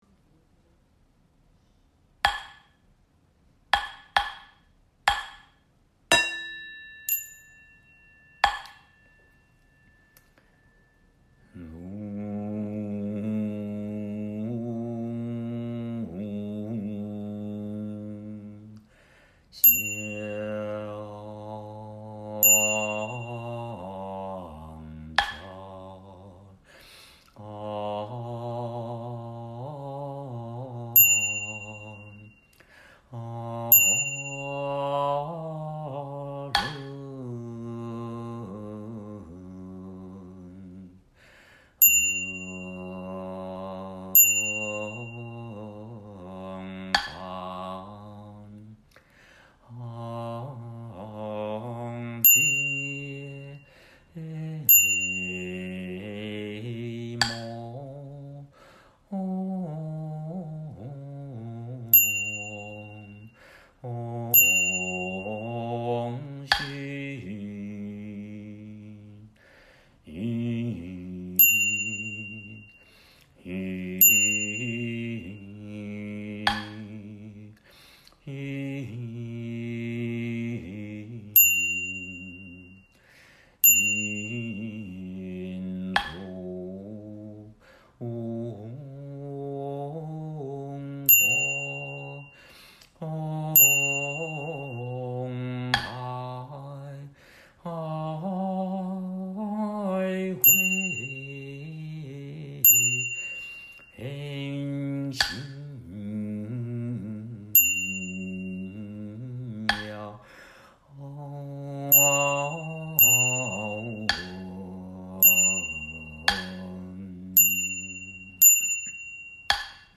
1、《佛前大供》梵呗仪轨及教学音档 佛教正觉同修会_如来藏网